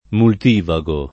[ mult & va g o ]